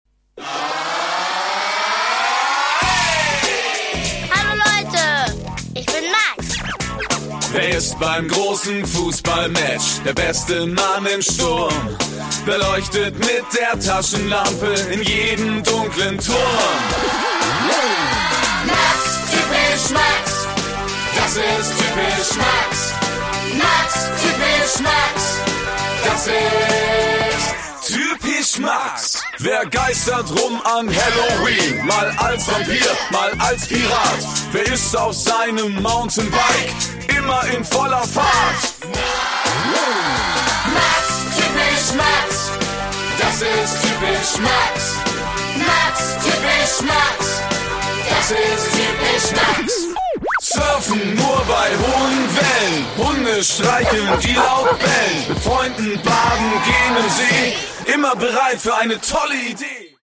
Produkttyp: Hörspiel-Download
Hörspielspaß für kleine und große Leute ab 6 Jahren!